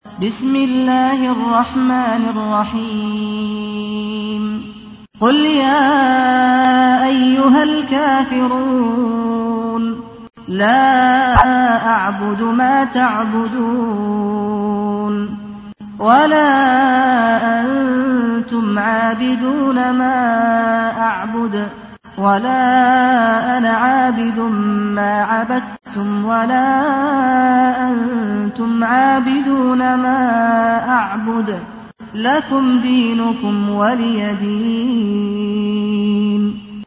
تحميل : 109. سورة الكافرون / القارئ سعد الغامدي / القرآن الكريم / موقع يا حسين